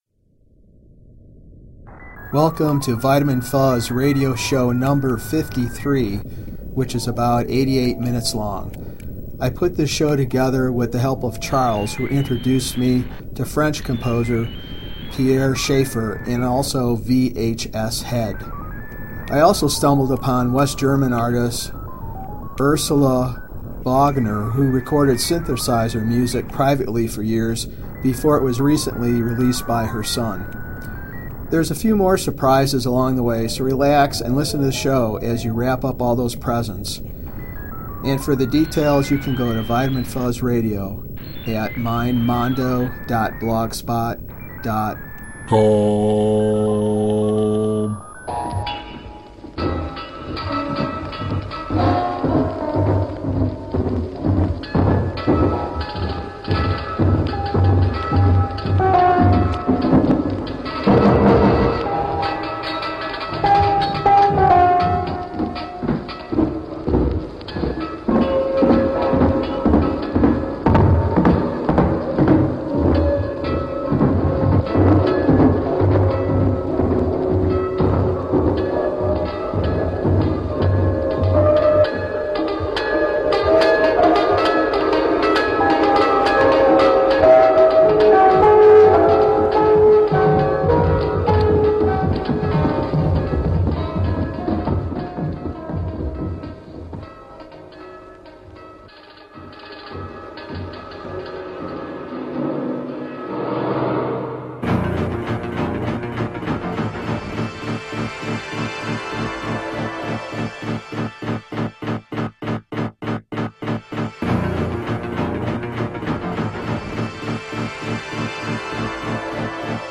Vitamin Fuzz Radio Collective has been transporting you toward unexplored Psychedelic skylines since 2008 with broadcasts via our PODCAST or mp3 downloads.
All music morphs from the noise that swirls by and through us. Noise and voice are the music.